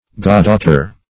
Search Result for " goddaughter" : Wordnet 3.0 NOUN (1) 1. a female godchild ; The Collaborative International Dictionary of English v.0.48: Goddaughter \God"daugh`ter\, n. [AS. goddohtor.]
goddaughter.mp3